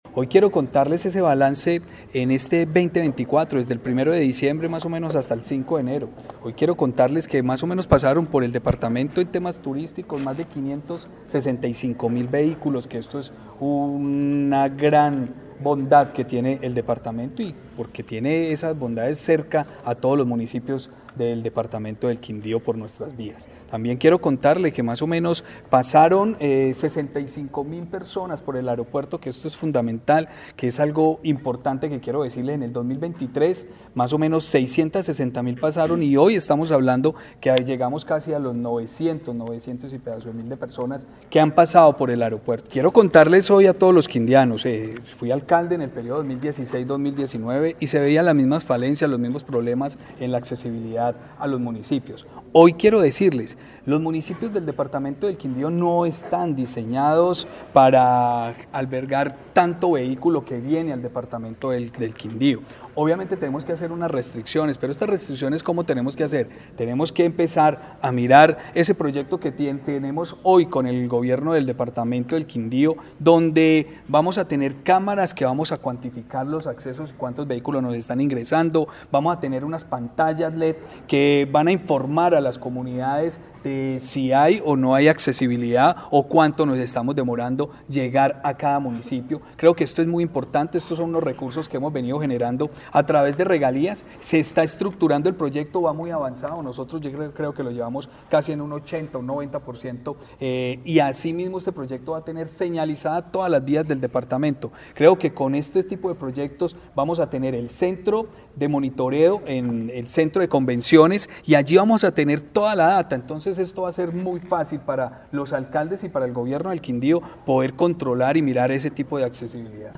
Gobernador del Quindío, Juan Miguel Galvis